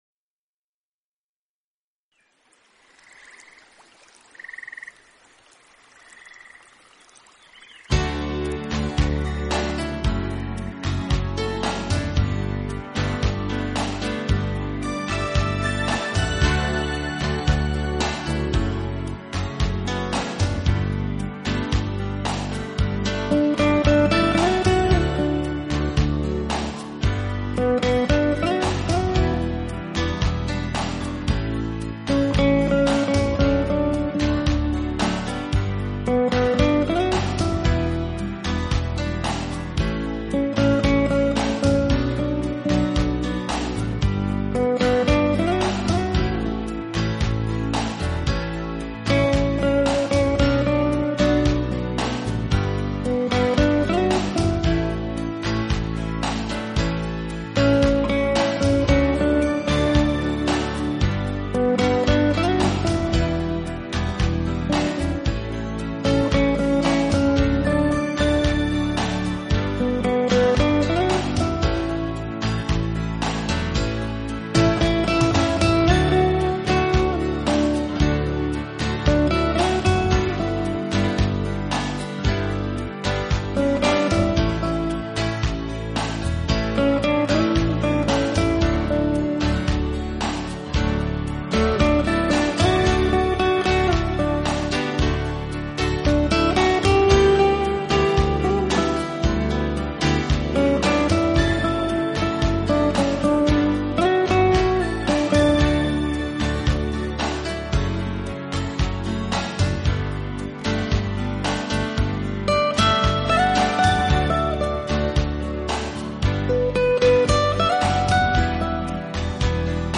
简单而清新的吉他声，Bossa Nova的慵懒节奏，
撩动各种怀旧声响制造的甜蜜音符，
辑的曲子是绝对松驰慵懒，放下压力，最适合在午后炙阳下，饮啜一